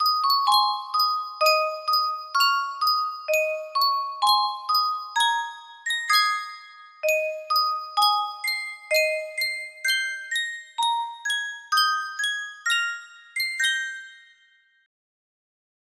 Yunsheng Music Box - The Yellow Rose of Texas Part 1 6092 music box melody
Full range 60